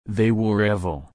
/ˈɹɛv.əl/